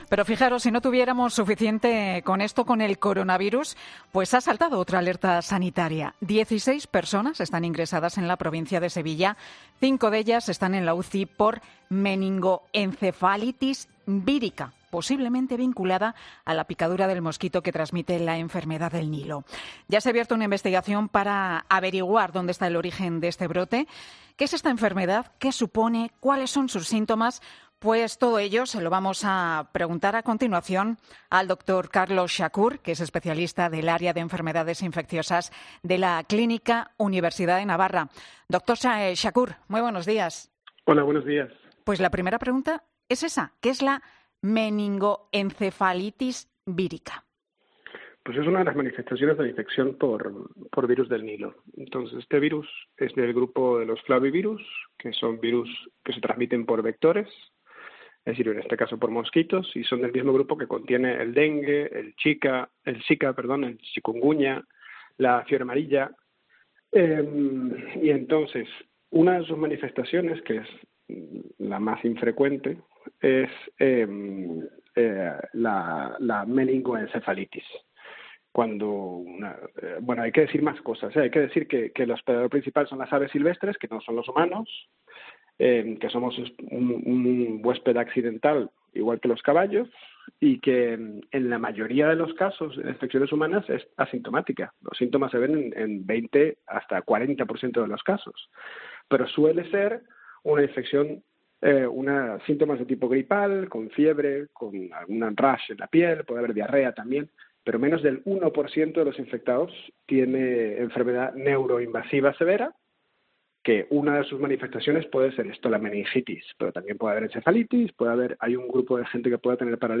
Doctor en Enfermedades Infecciosas, sobre el brote meningoencefalitis vírica: "No se contagia entre personas"